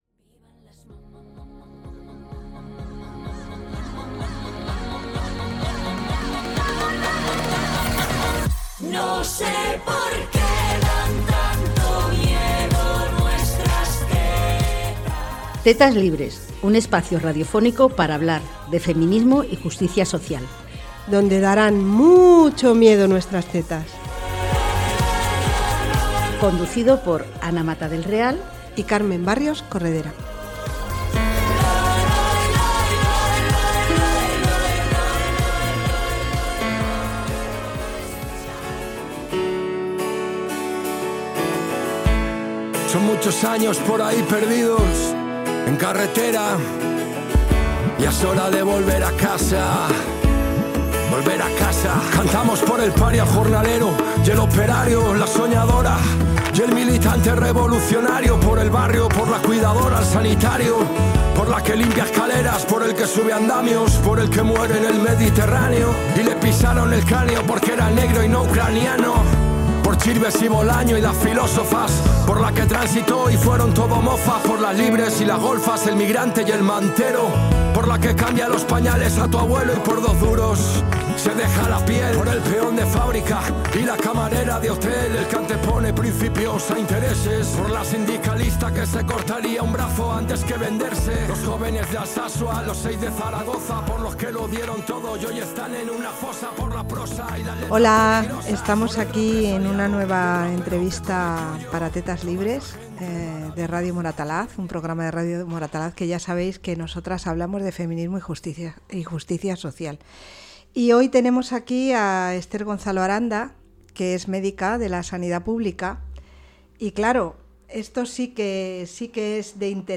En la entrevista dialogan de la situación de la sanidad en Madrid, sobre la importamcia de la salud comunitaria pública y de la educación para la